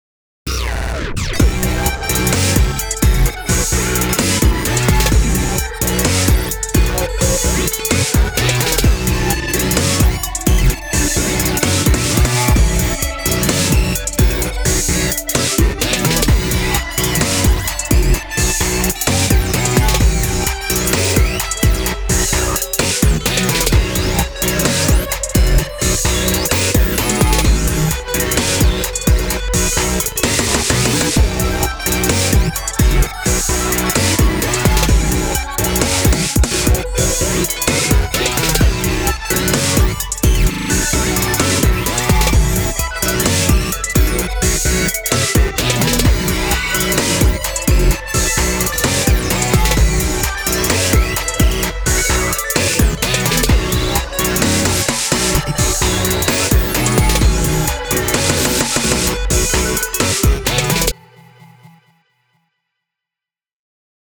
EDM
シンセ
クール